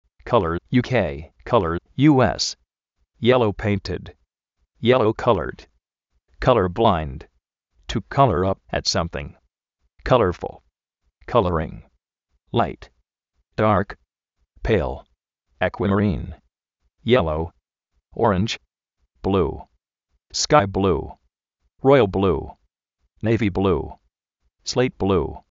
káler (UK), kólor (US)
gréi (UK), gréi (US)